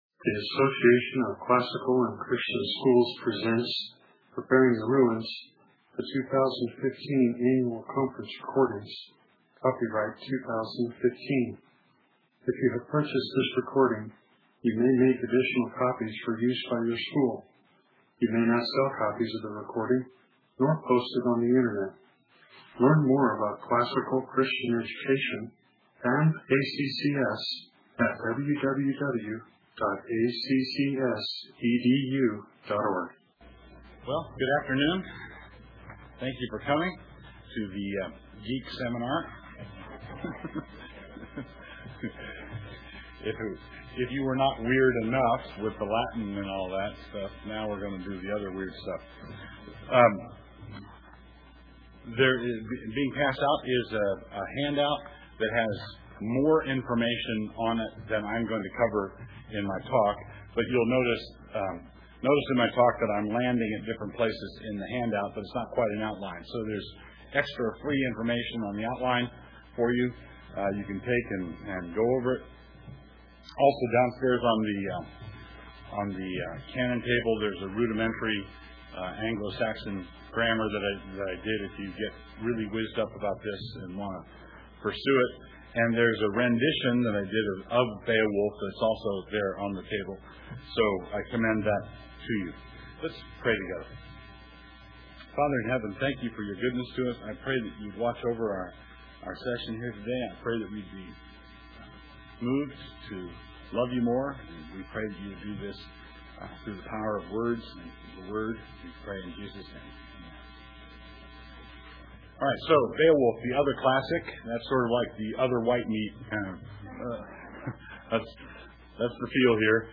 2016 Workshop Talk | 1:01:40 | 7-12, Academics & Curriculum, Literature
Additional Materials The Association of Classical & Christian Schools presents Repairing the Ruins, the ACCS annual conference, copyright ACCS.